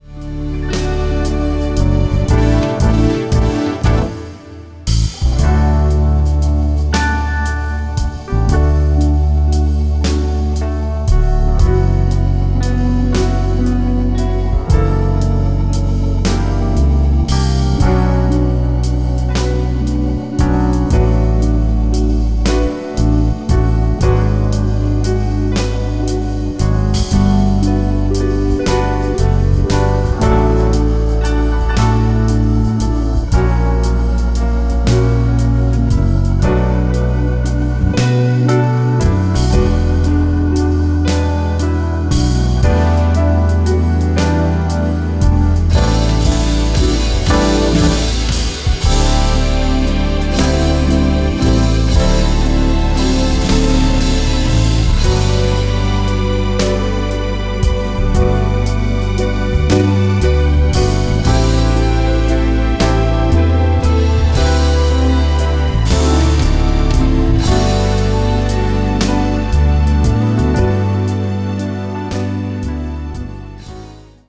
No lead guitar solo.
Key of D
Backing track only.